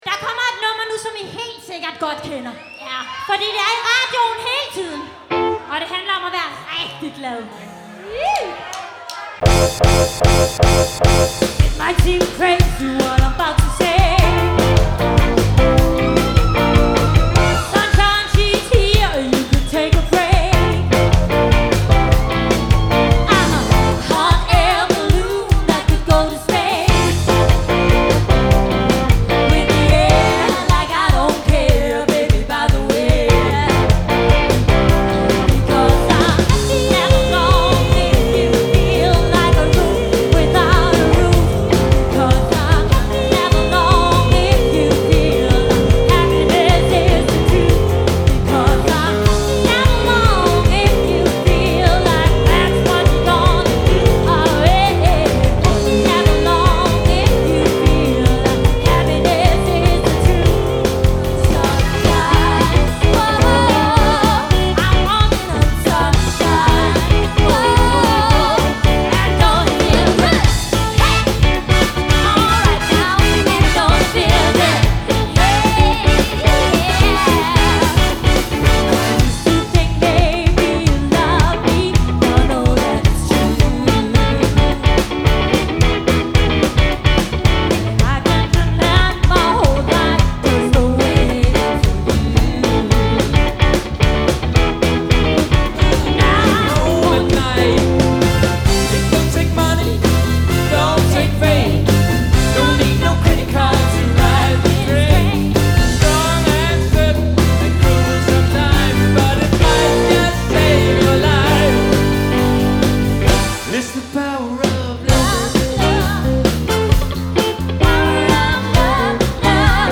Live band m/ indlevelse og top underholdning.
• Coverband
• 1. Maj i Fælledparken. (Engelsk Medley live)